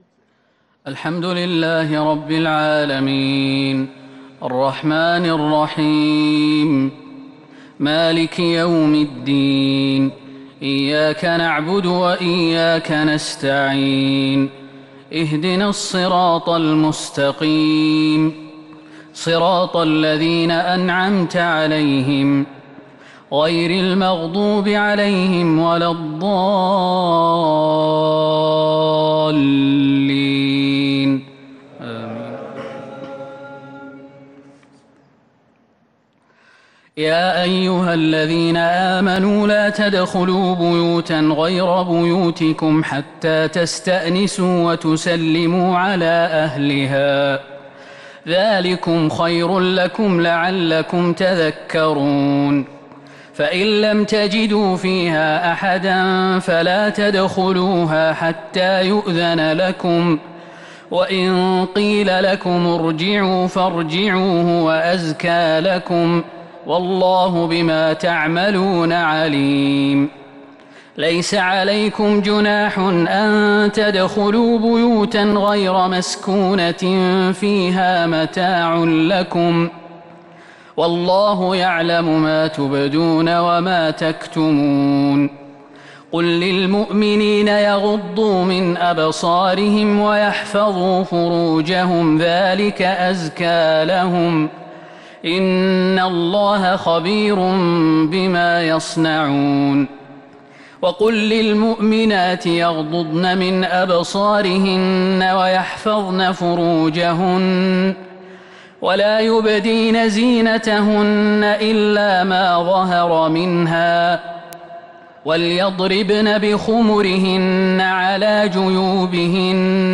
صلاة التهجّد | ليلة 22 رمضان 1442| من سورة النور 27 - 64 | Tahajjud prayer | The night of Ramadan 22 1442 | Surah An-Nur > تراويح الحرم النبوي عام 1442 🕌 > التراويح - تلاوات الحرمين